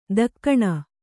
♪ dakkaṇa